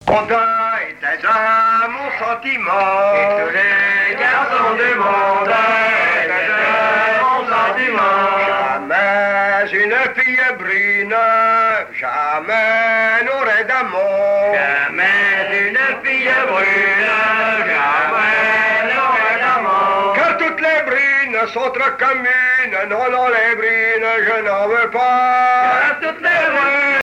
Genre strophique
répertoire de bals et de noces
Pièce musicale inédite